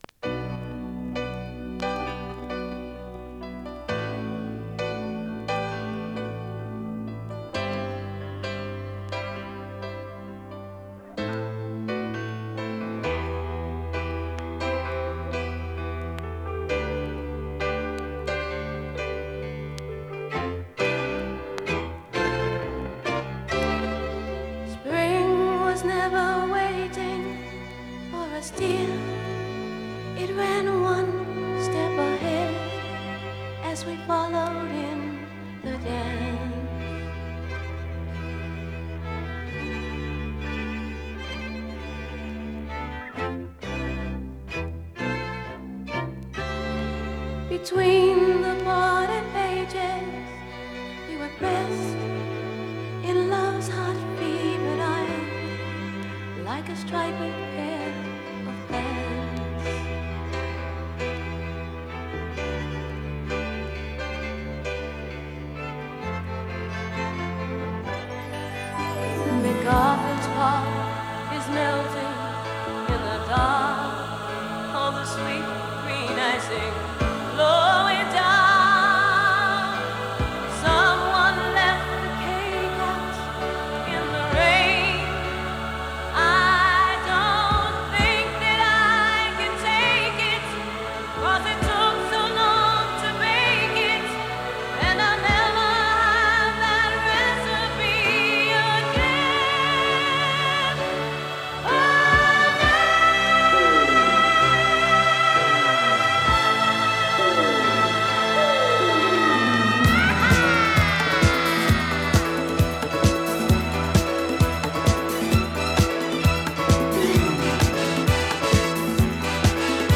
躍動 ディスコ ソウル
ドラマチックで躍動感溢れるディスコ・クラシック！